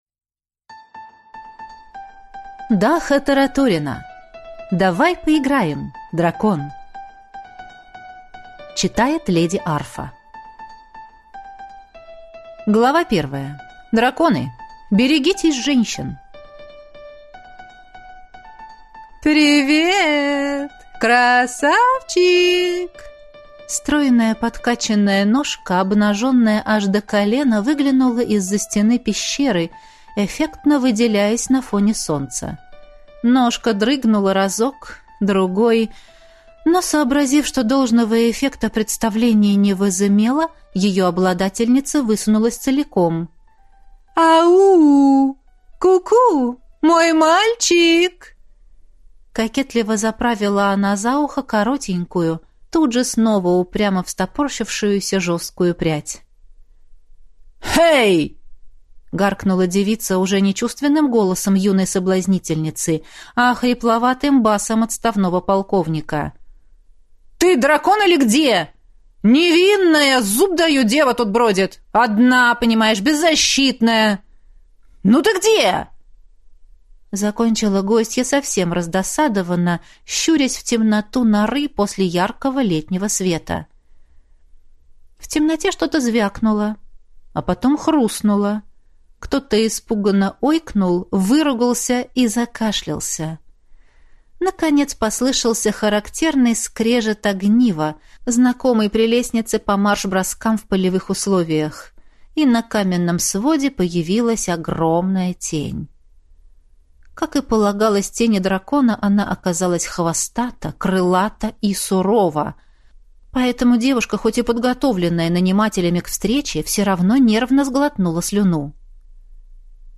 Аудиокнига Давай поиграем, дракон!